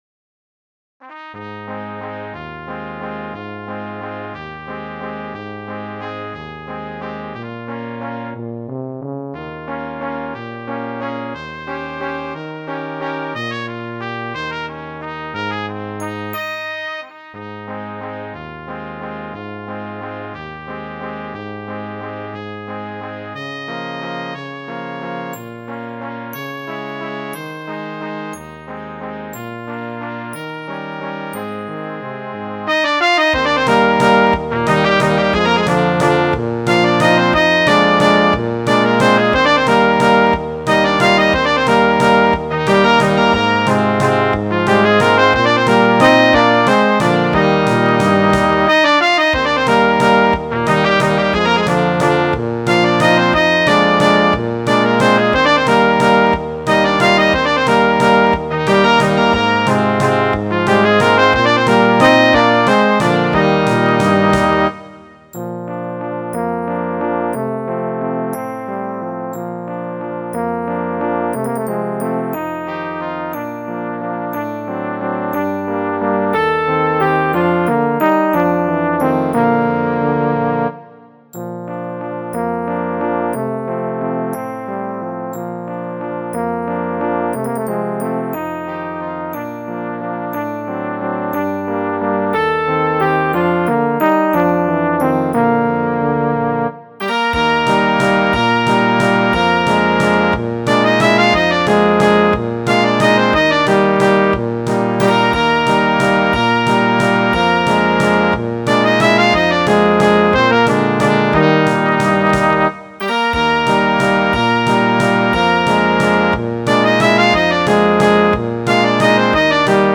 Music for Brass
Grand Waltz for Guitar